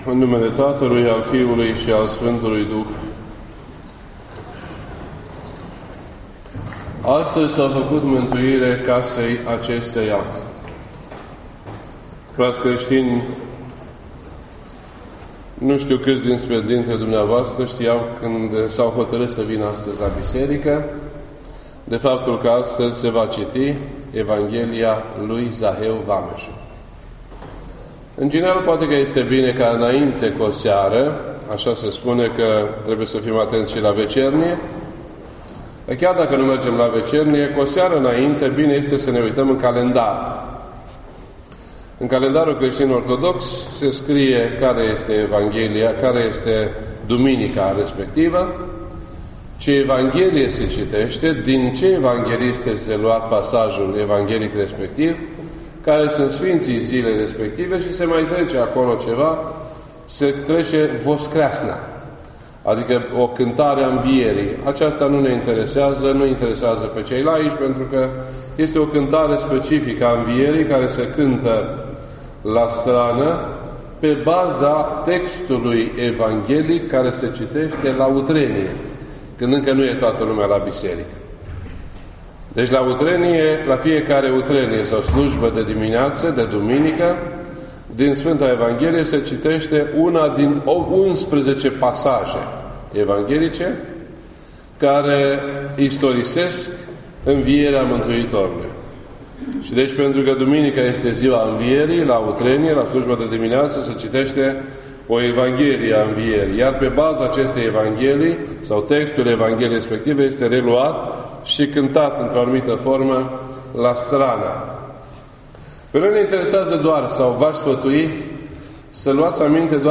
This entry was posted on Sunday, January 21st, 2018 at 1:45 PM and is filed under Predici ortodoxe in format audio.